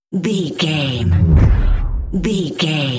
Deep whoosh to hit sci fi
Sound Effects
dark
futuristic
tension
woosh to hit